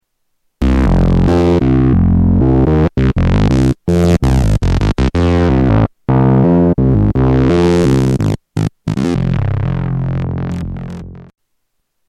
Tags: Sound Effects EML ElectroComp 101 EML101 ElectroComp 101 Synth Sounds